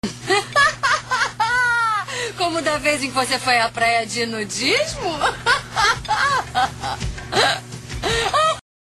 Paola Risada